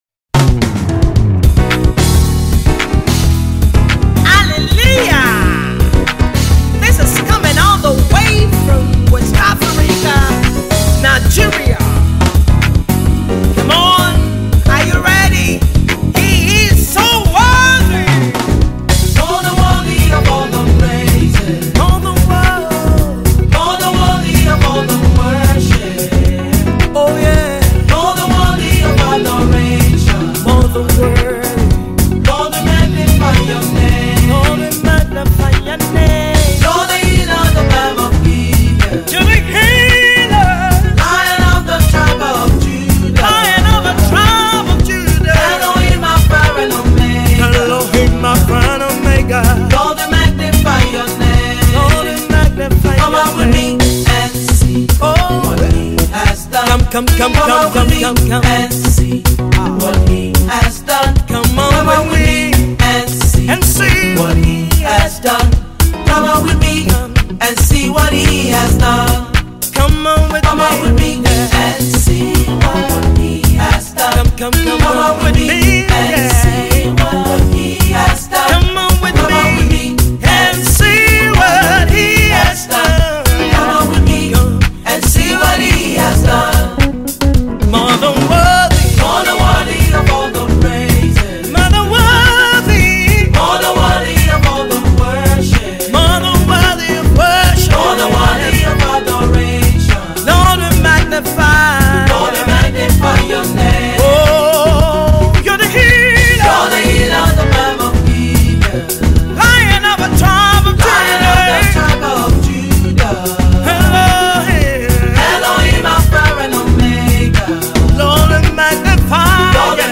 the legendary Nigerian gospel quartet